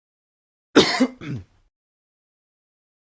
Man COugh
Man COugh is a free sfx sound effect available for download in MP3 format.
yt_UW9aeqnwf5c_man_cough.mp3